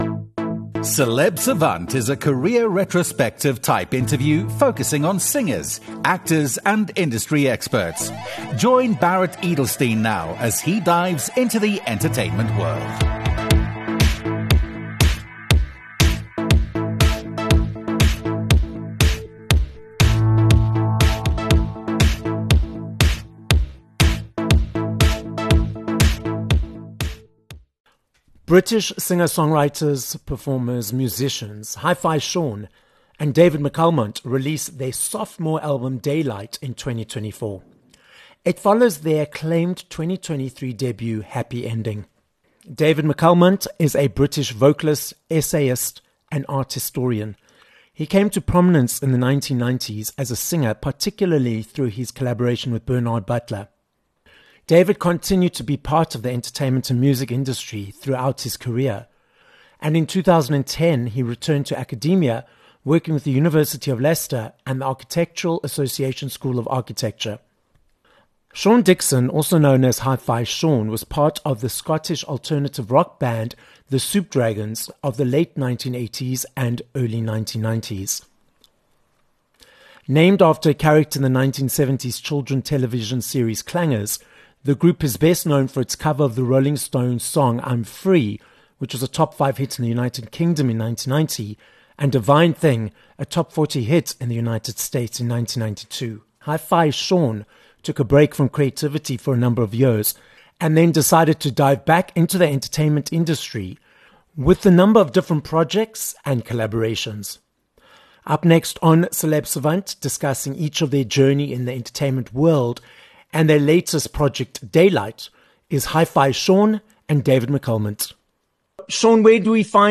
11 Aug Interview with HiFi Sean and David McAlmont